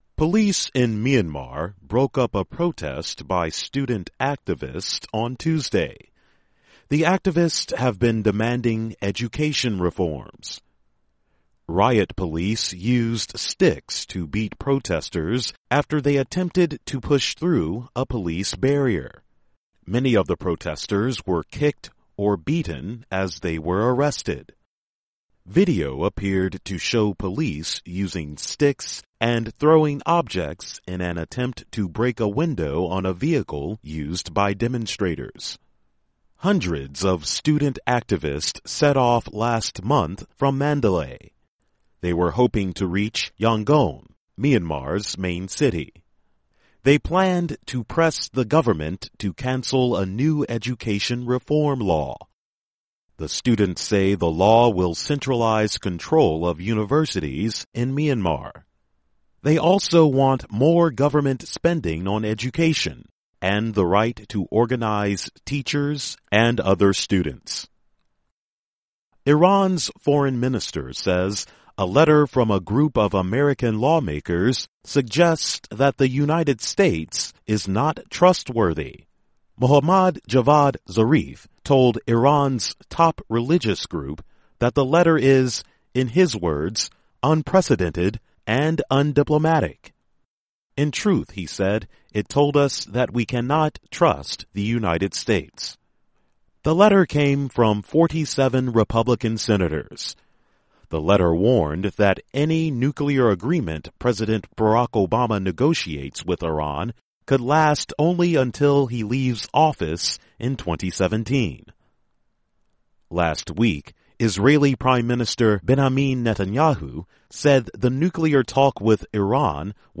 This newscast was based on stories from VOA’s News Division.